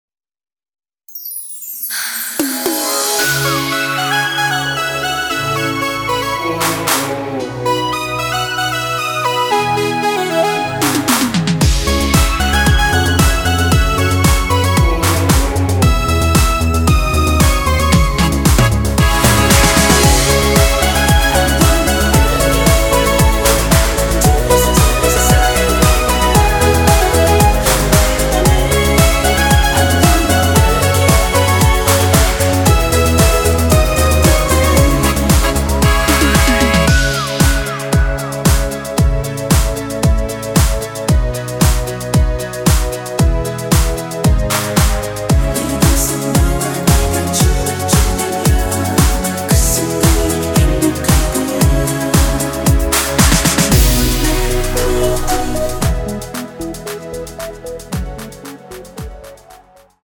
원키에서(-1)내린 코러스 포함된 MR입니다.(미리듣기 확인)
C#m
앞부분30초, 뒷부분30초씩 편집해서 올려 드리고 있습니다.